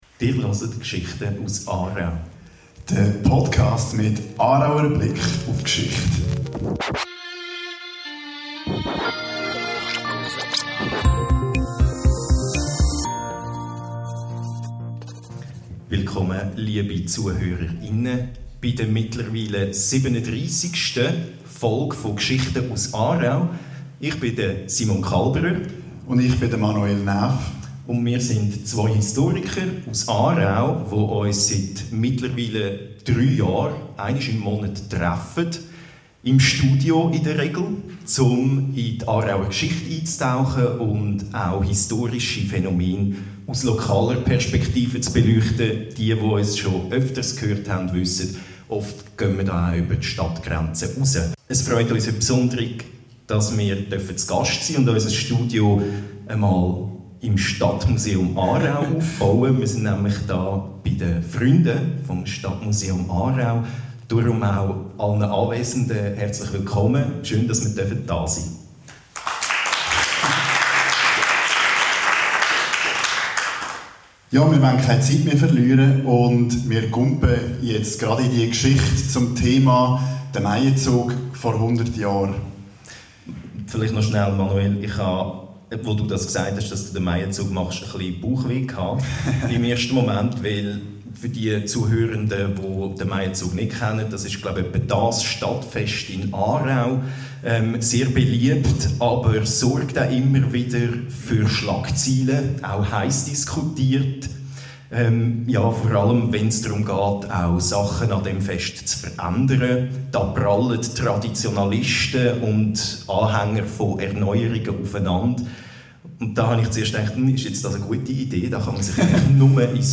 Wir waren wieder einmal live vor Publikum.